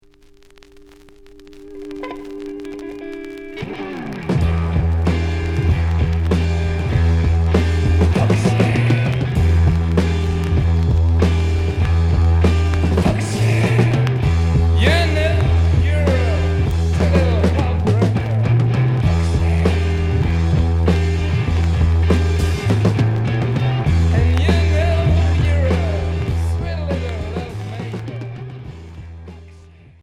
Heavy rock Hendrixien